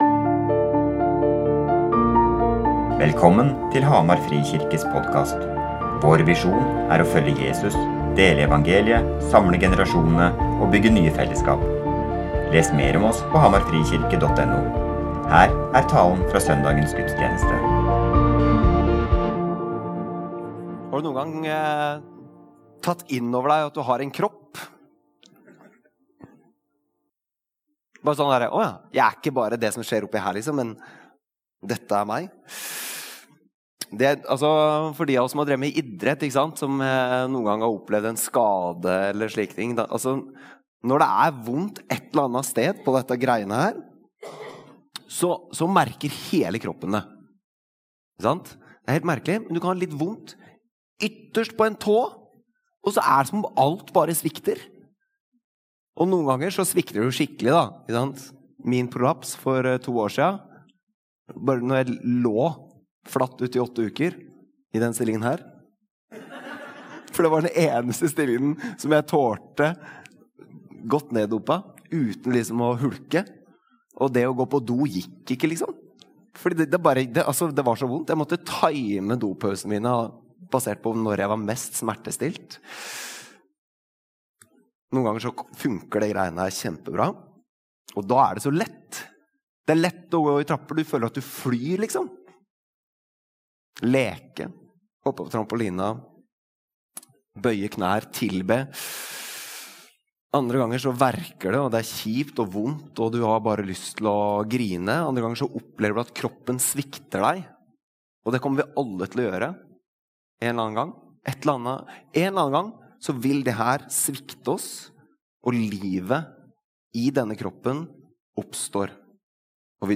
Gudstjenesten